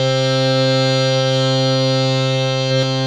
52-key06-harm-c3.wav